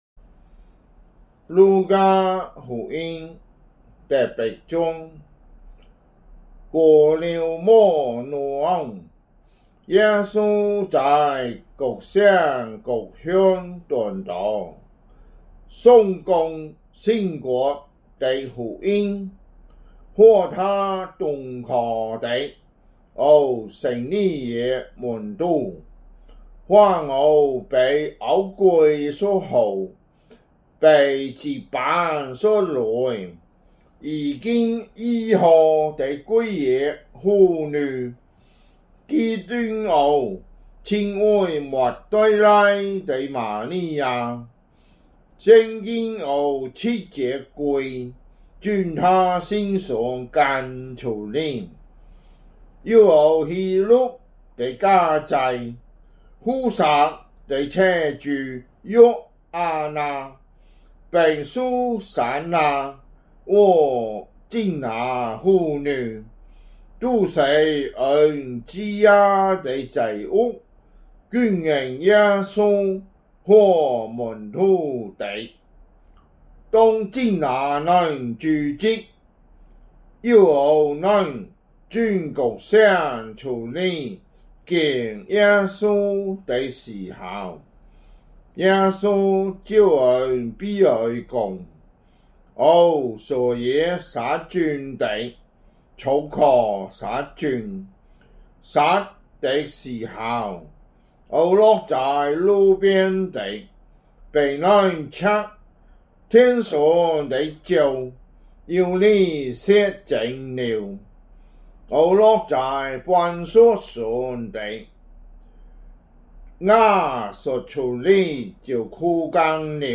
福州話有聲聖經 路加福音 8章